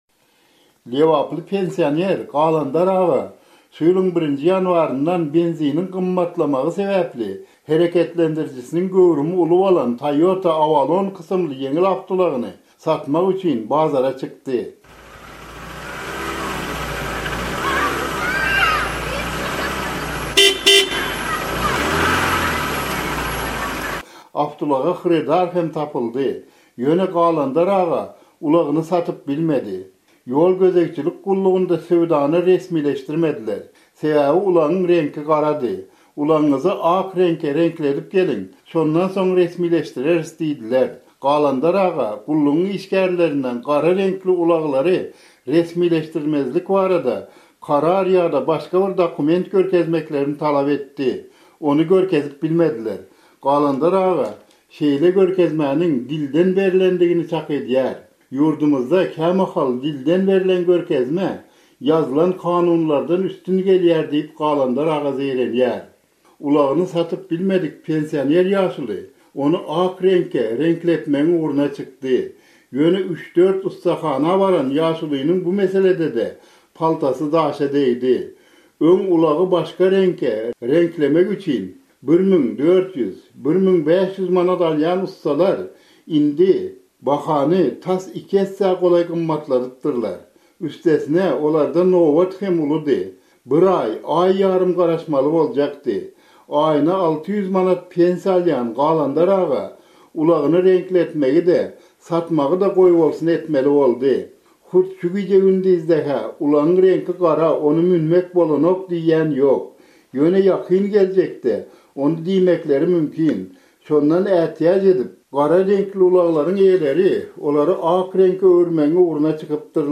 Türkmenabat, Lebap welaýaty.